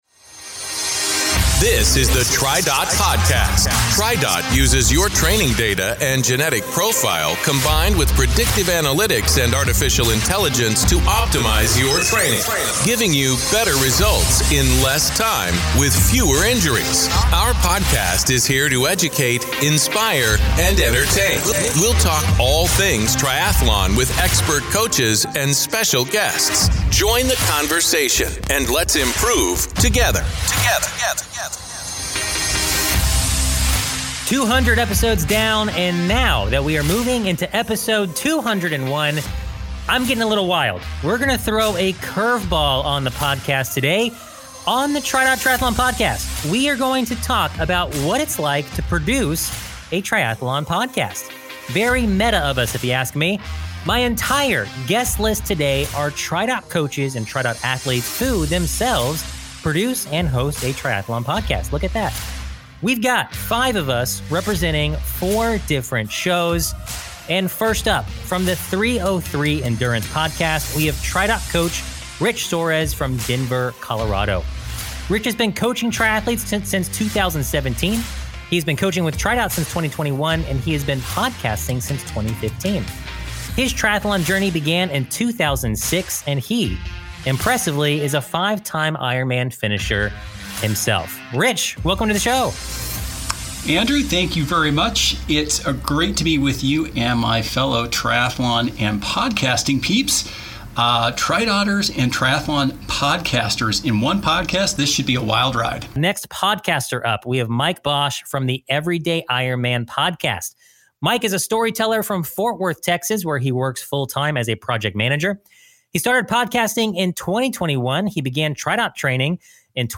Cue the blooper reel!